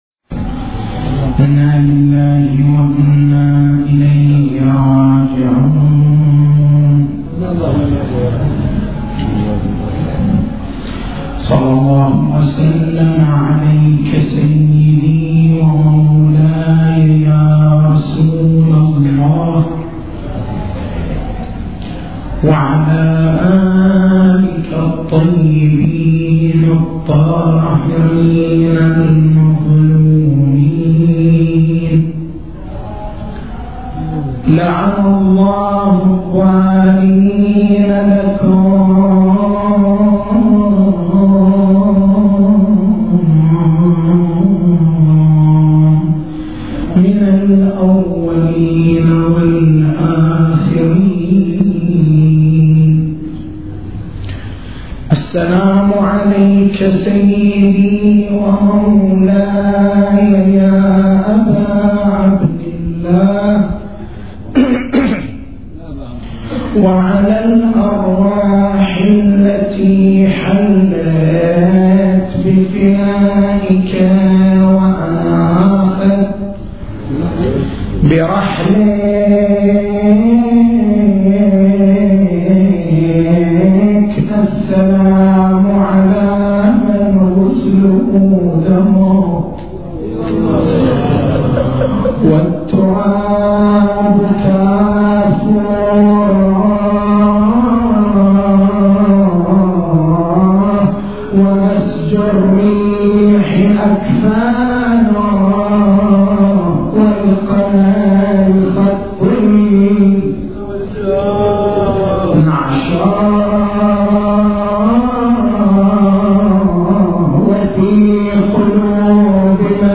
تاريخ المحاضرة: 13/01/1427 نقاط البحث: البعد الإحساسي البعد التعريفي البعد العقائدي البعد العاطفي البعد السلوكي التسجيل الصوتي: تحميل التسجيل الصوتي: شبكة الضياء > مكتبة المحاضرات > محرم الحرام > محرم الحرام 1427